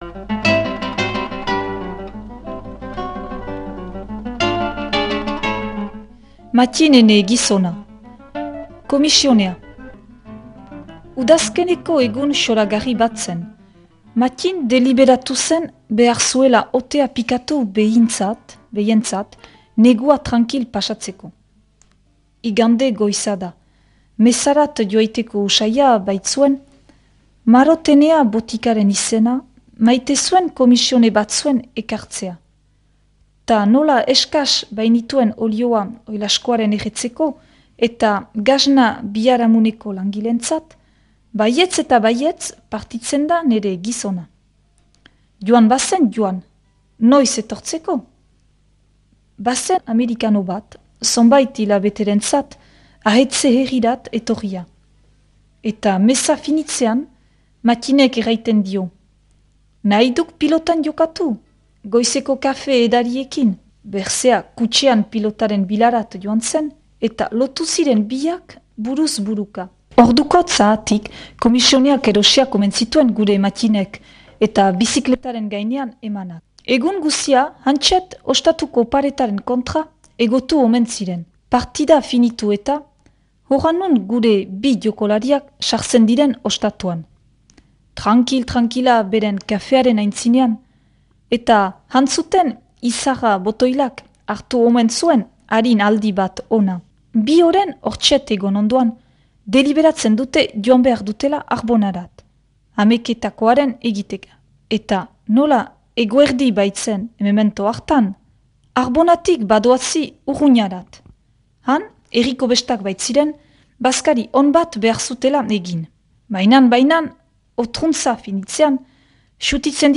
irakurketa